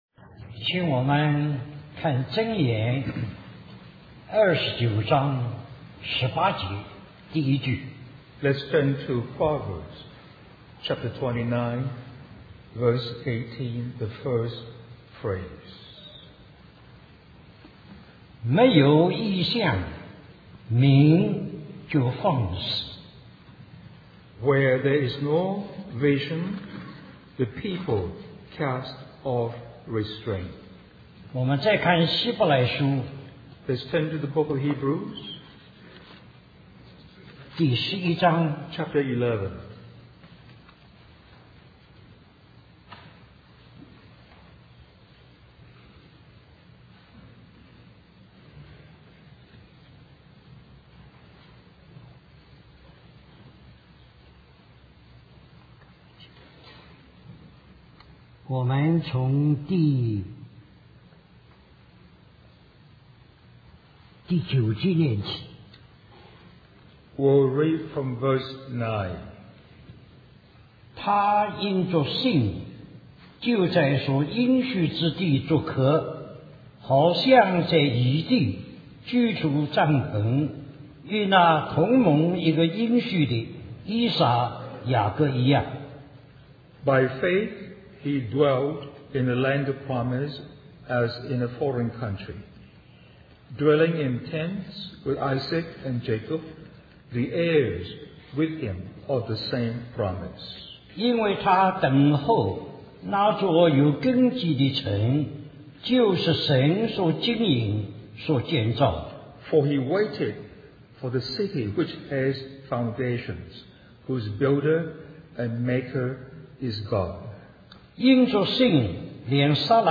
Special Conference For Service, Taipei, Taiwan